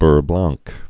(bûr blängk, bœr bläɴ)